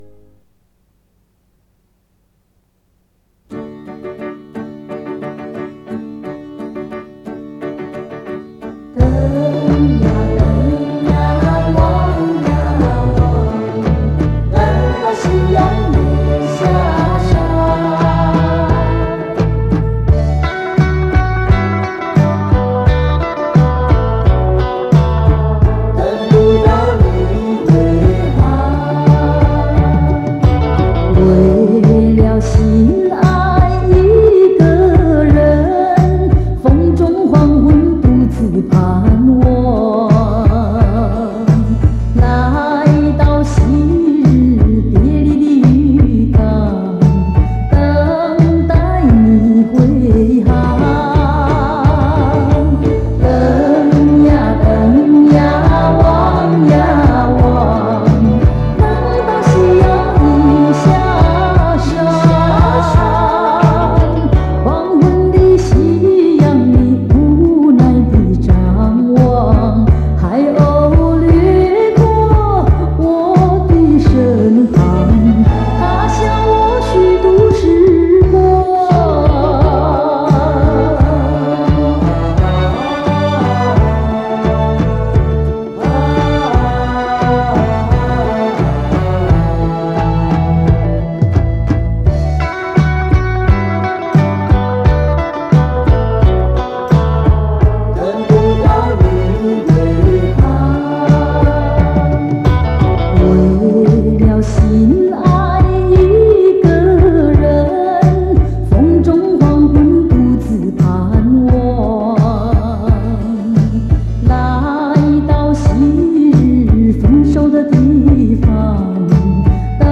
磁带数字化：2022-11-12